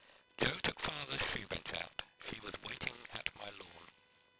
Note that the residual, in figure 5.6 (b), is a lower-amplitude signal than the original. The prediction residual has sharp spikes at regular intervals, corresponding to the glottal pulses of the speech wave.